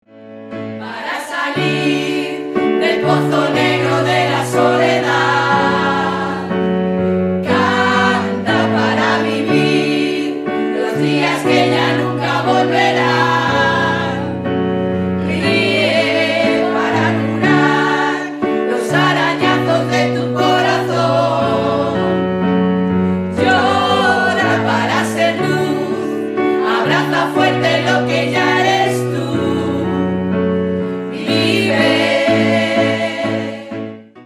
y en muchas ocasiones lo hace a través de sus canciones reivindicativas.
actuación de su coro formato MP3 audio(0,78 MB).